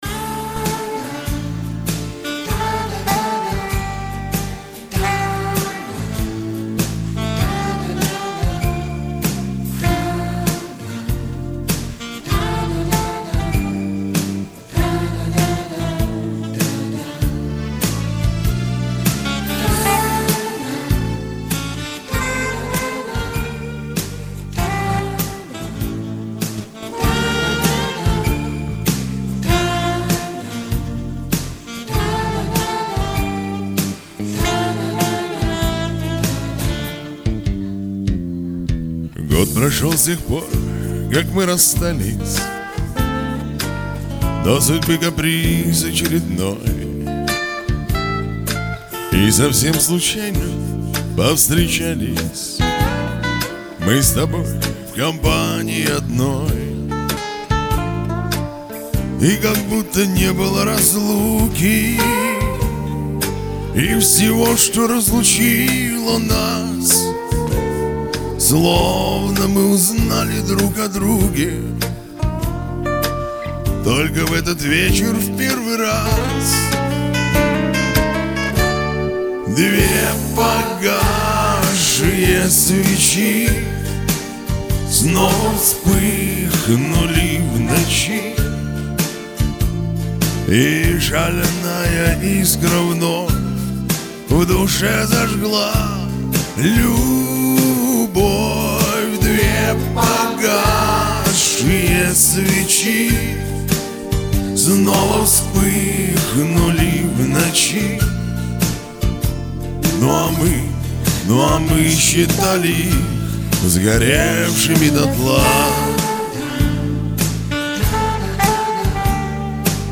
Проще говоря, я пародист.
Настоящий шансон!!Молодцы!!!